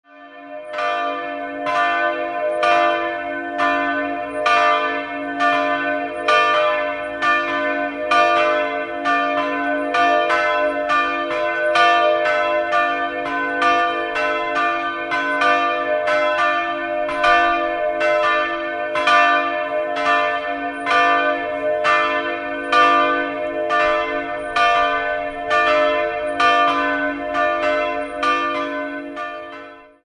2-stimmiges Kleine-Terz-Geläute: h'-d'' Große Glocke h' 320 kg 811 mm
Kleine Glocke d'' 175 kg 700 mm